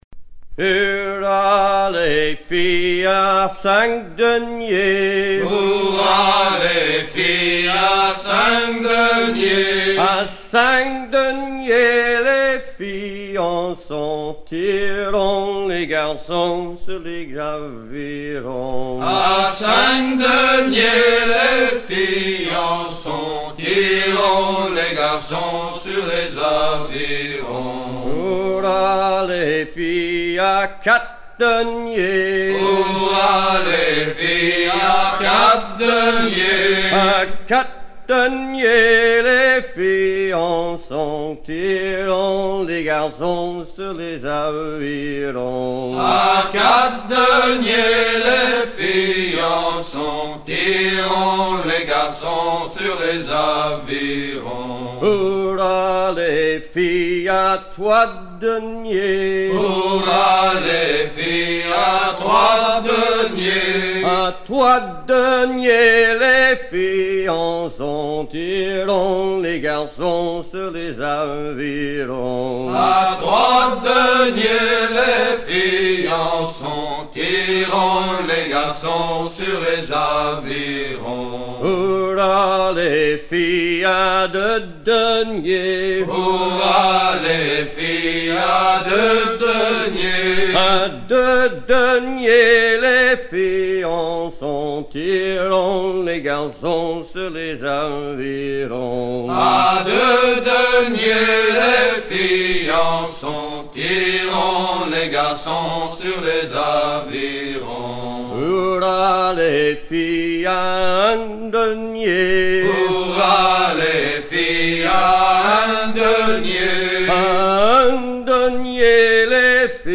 gestuel : à ramer
circonstance : maritimes ; gestuel : travail
Genre énumérative
Pièce musicale éditée